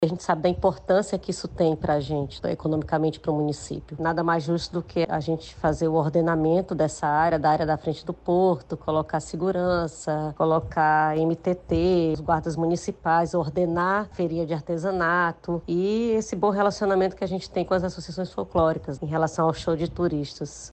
A secretária, também, ressalta a importância da atividade para a região, com a implementação de medidas para melhor atender os visitantes.
SONORA-2-TEMPORADA-CRUZEIROS-PARINTINS-.mp3